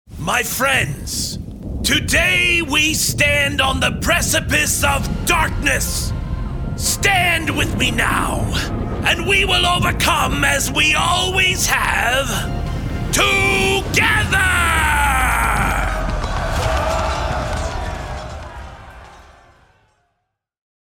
Male
Adult (30-50), Older Sound (50+)
Video Games
Cutscene End Charge.